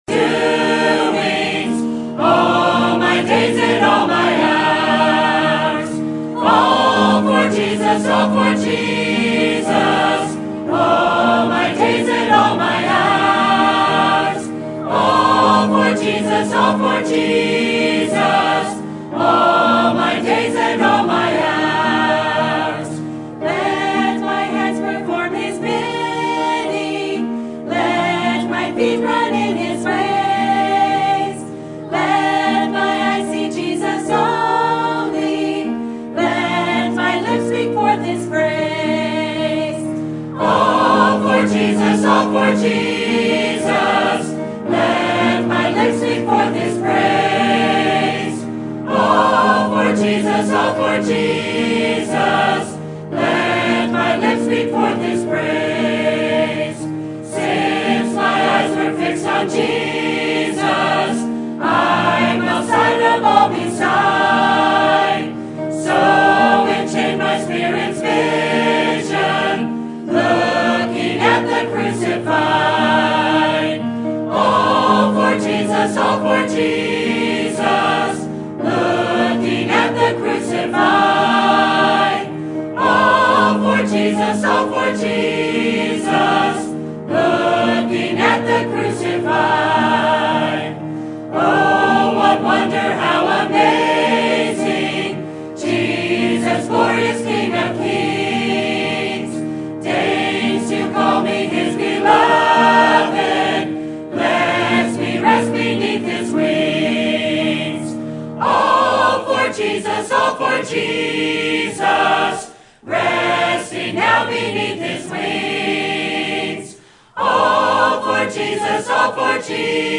Sermons List | Calvary Baptist Church